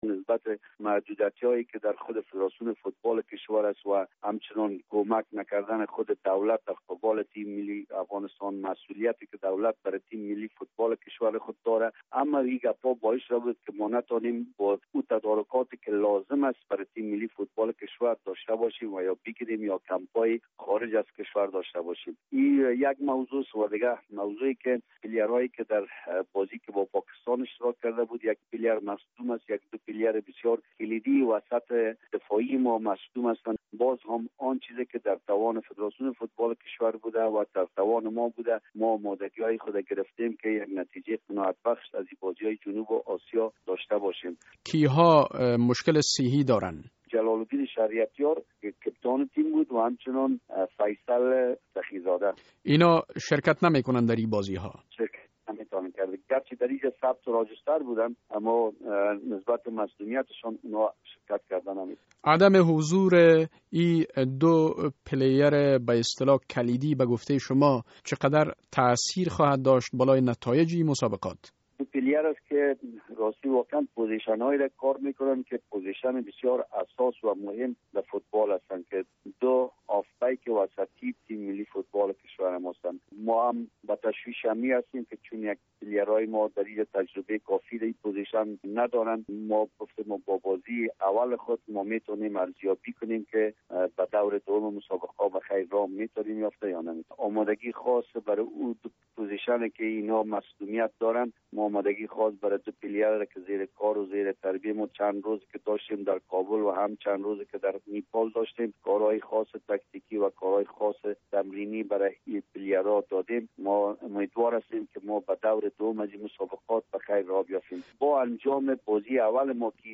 مصاحبه: فوتبالران افغان در رقابت های جنوب آسیا با بوتان مسابقه دارند